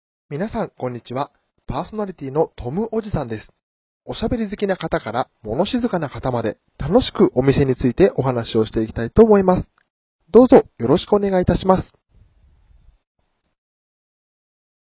独特の語り口と穏やかな声質で、和みのある楽しい雰囲気を上手に演出できる、店ラジ看板MCです。